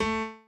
admin-fishpot/b_pianochord_v100l16-3o4a.ogg at main